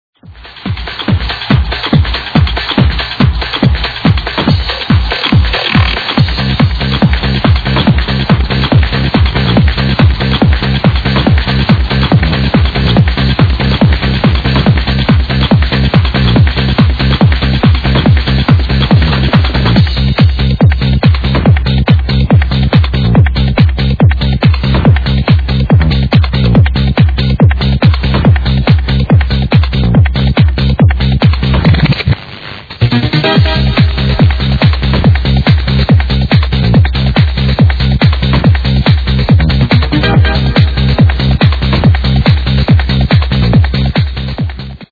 I ripped this from an old recorded mix from radio..... does anyone recognise it?